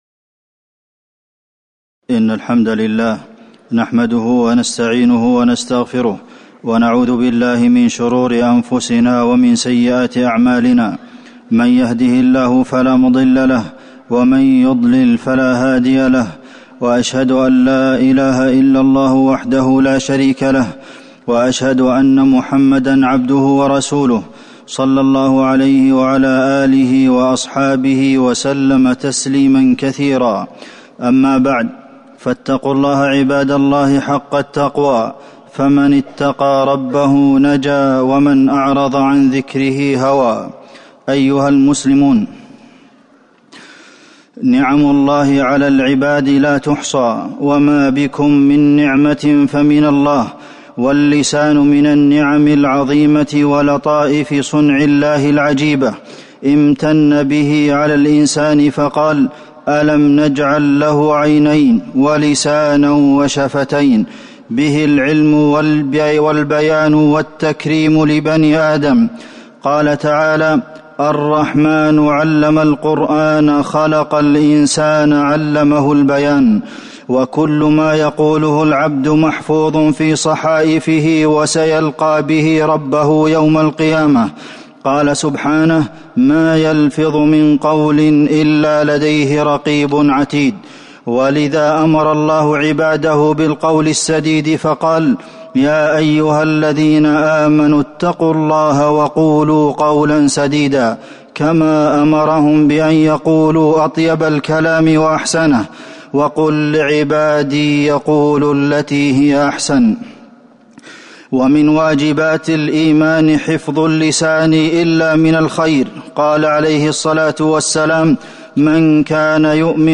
تاريخ النشر ٤ رجب ١٤٤١ هـ المكان: المسجد النبوي الشيخ: فضيلة الشيخ د. عبدالمحسن بن محمد القاسم فضيلة الشيخ د. عبدالمحسن بن محمد القاسم حفظ اللسان